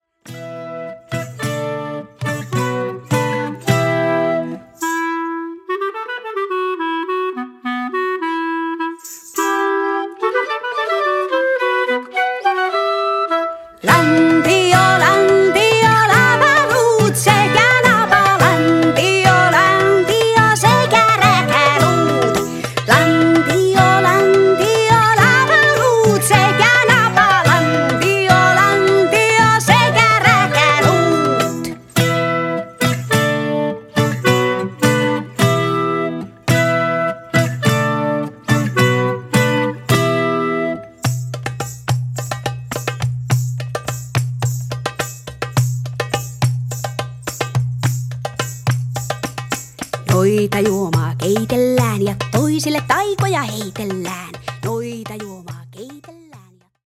keskiaikahenkinen laulu